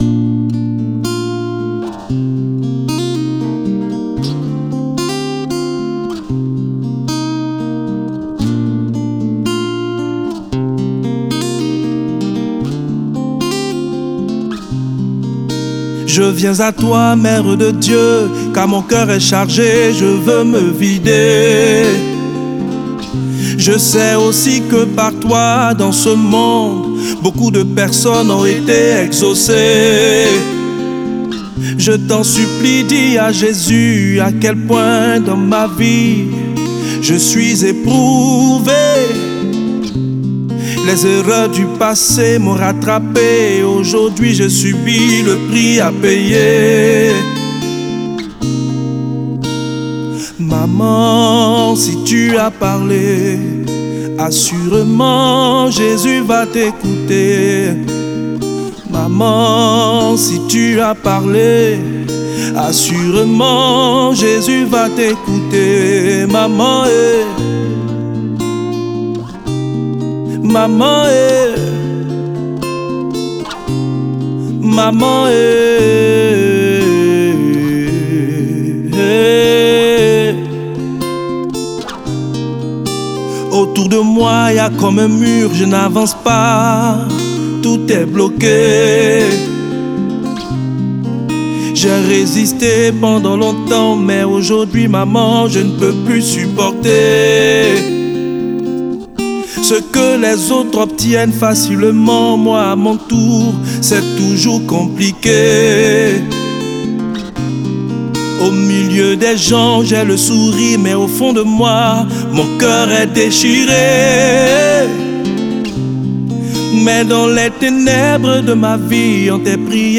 Nouveau single du chantre